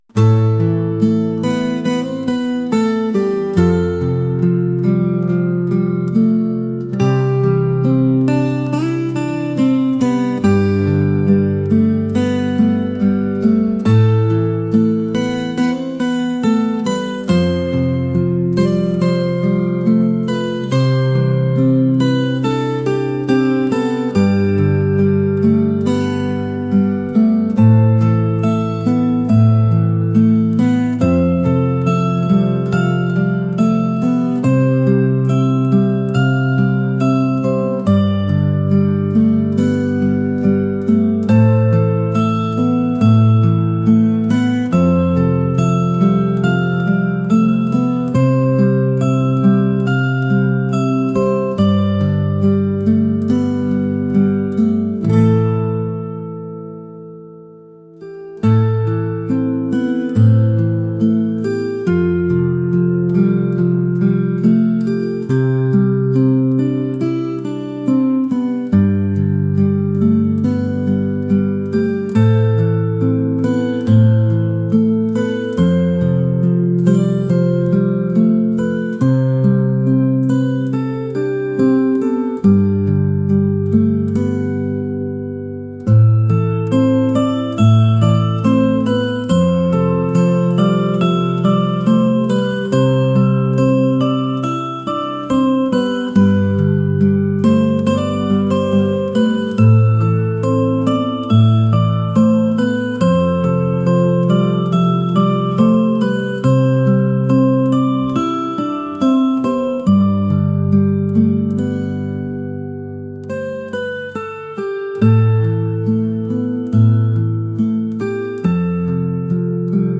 acoustic